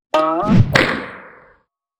slingshot.wav